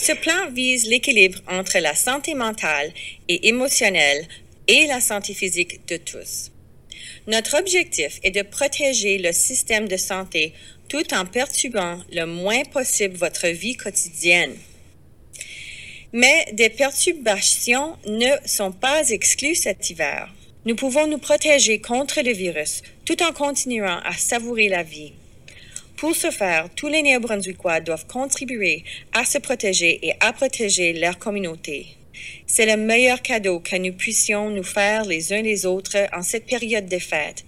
La médecin-hygiéniste en chef, la Dre Jennifer Russell, parle du plan d'hiver en conférence de presse :
Extrait-Jennifer-Russell.mp3